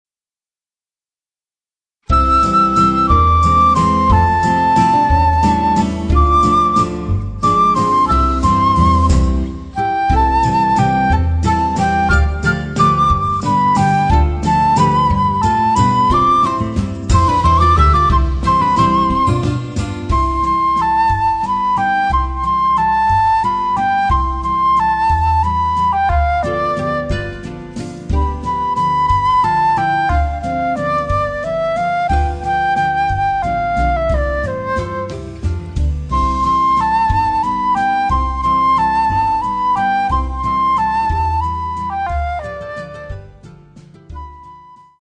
12 Vibrant themes with Latin flavour and spirit